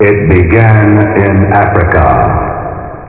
home *** CD-ROM | disk | FTP | other *** search / AMOS PD CD / amospdcd.iso / 451-475 / apd472 / samples / itbeganinafrica ( .mp3 ) Amiga 8-bit Sampled Voice | 1993-03-13 | 16KB | 1 channel | 4,971 sample rate | 3 seconds